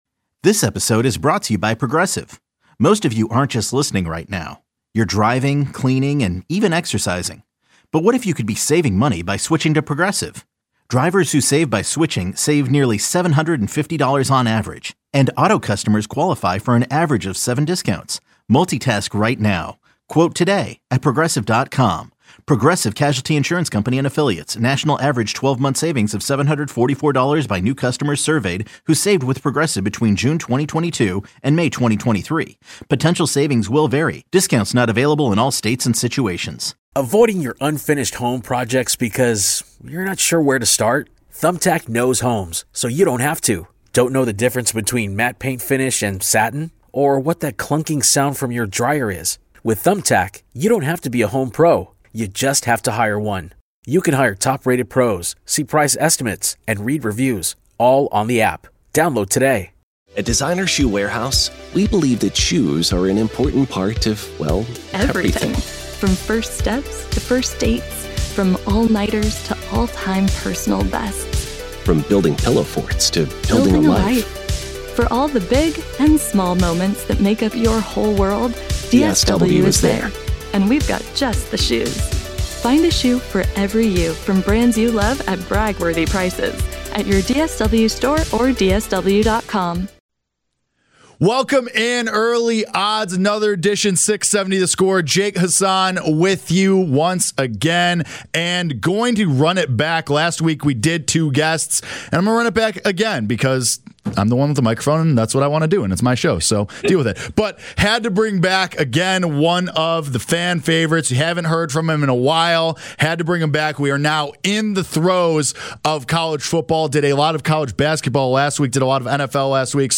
The show airs at 8 a.m. on Saturdays on 670 The Score.